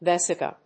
/ˈvɛsɪkə(米国英語)/